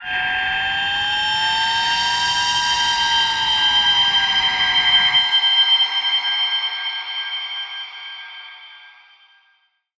G_Crystal-G7-f.wav